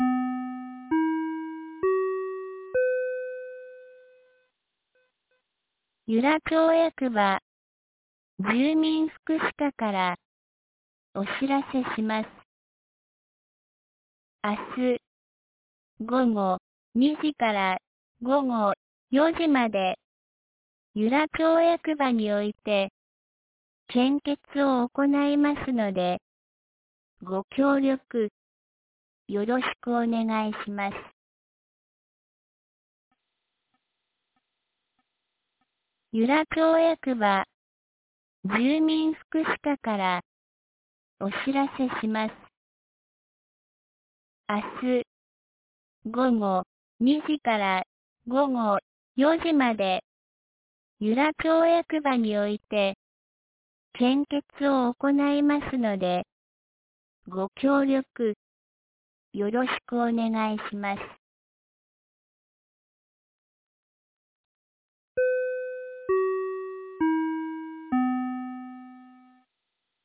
2025年08月18日 12時21分に、由良町から全地区へ放送がありました。